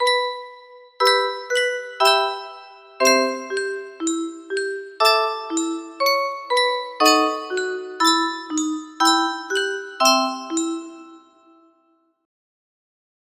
Full range 60
A short test of this tune I really love